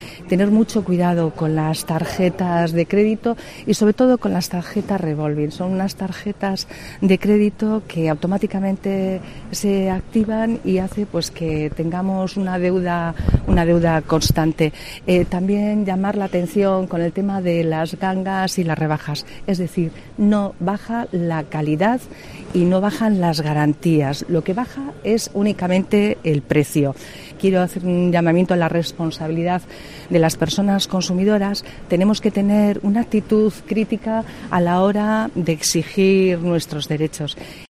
La edil de consumo de Bilbao, Yolanda Díez, ofrece consejos ante el arranque de las rebajas